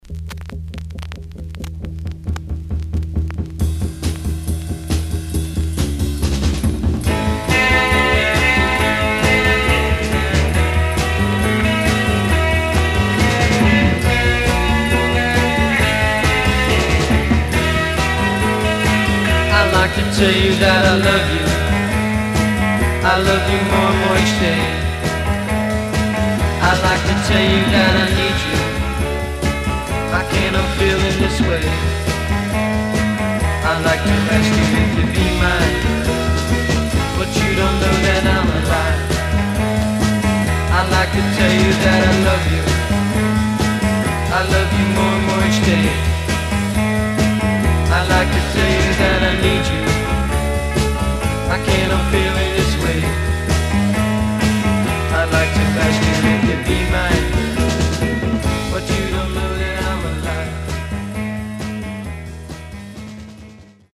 Surface noise/wear Stereo/mono Mono
Garage, 60's Punk